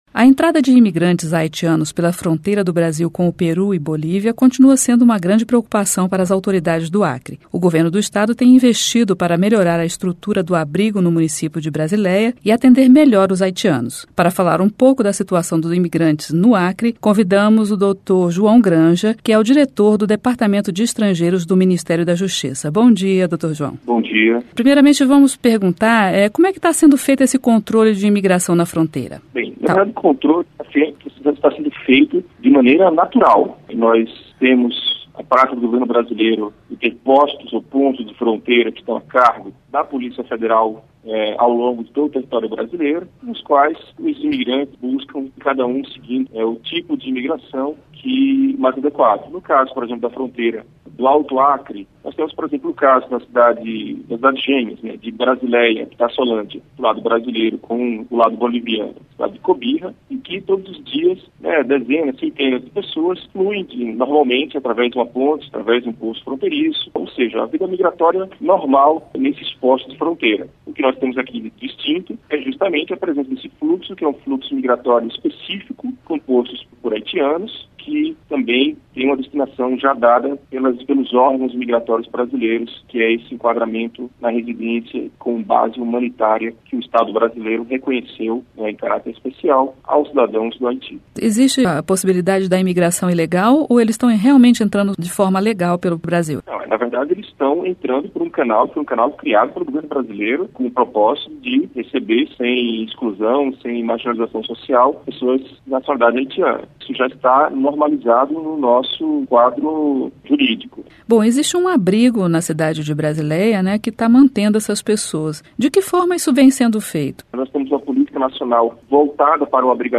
Entrevista: Imigração de Haitianos pela fronteira do Acre Entrevista com o diretor do Departamento de Estrangeiros do Ministério da Justiça, João Granja.